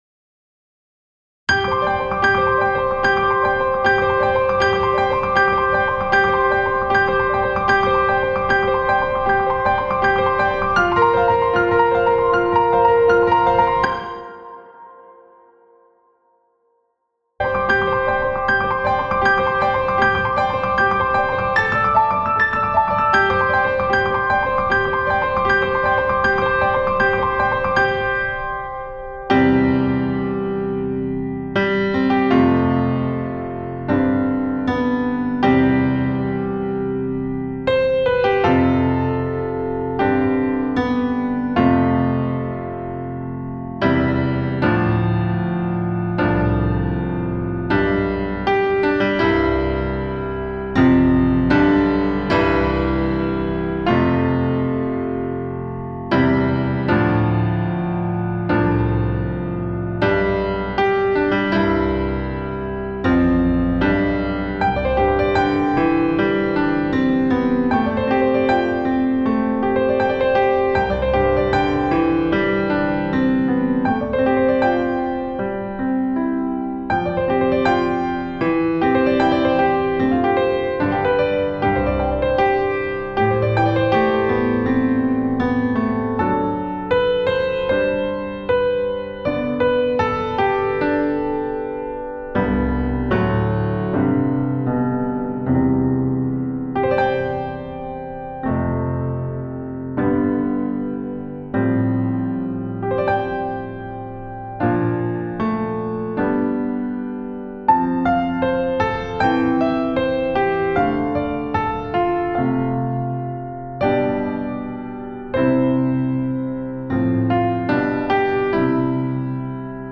Instrumentation: Violin and Piano